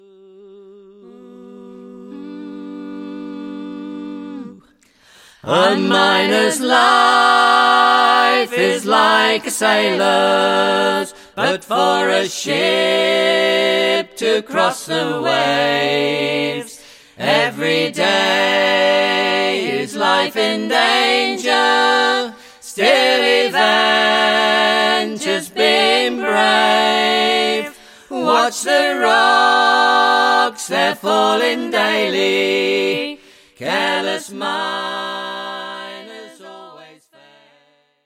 at Rooksmere Studios